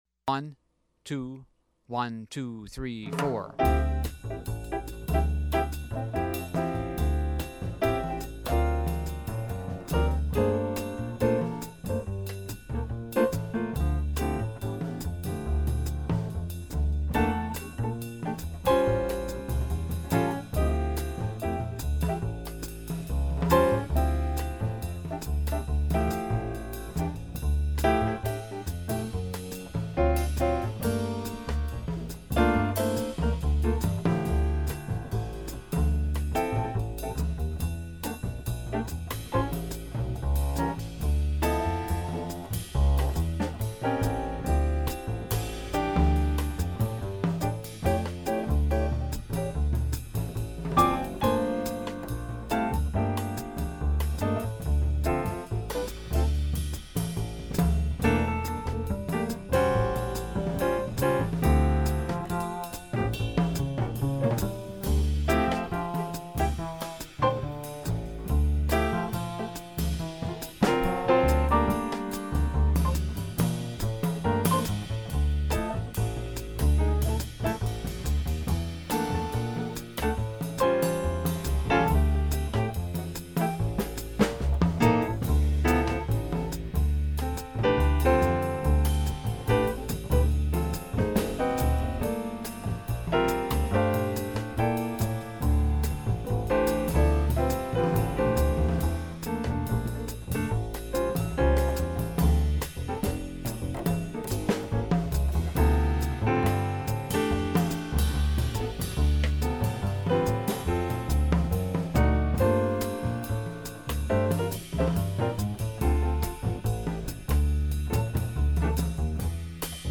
remineurBlues.mp3